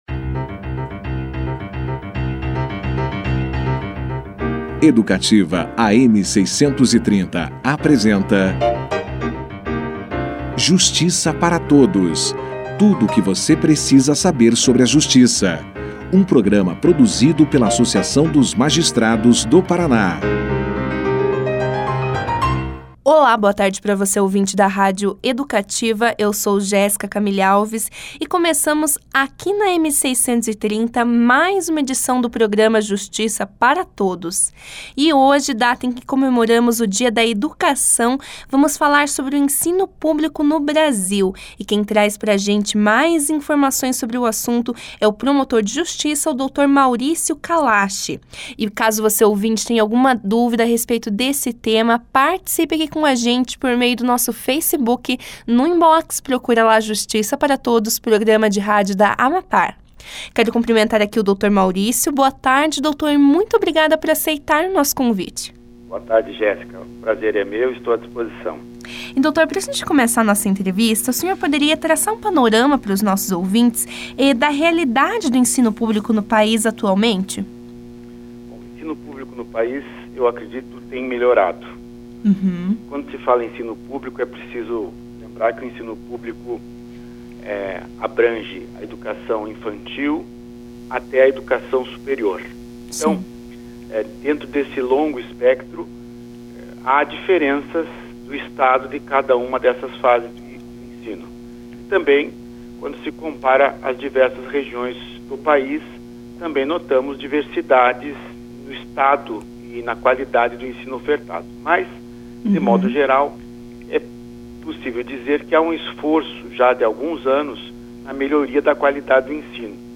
Durante a conversa, foram discutidas ainda as mudanças curriculares previstas na Reforma do Ensino Médio; a responsabilidade dos pais no relacionamento com a instituição de ensino dos filhos e os direitos dos alunos portadores de deficiência à educação pública. O promotor também comentou sobre os atuais problemas no ensino superior gratuito, como o baixo investimento na infraestrutura dessas instituições. Confira aqui a entrevista na íntegra.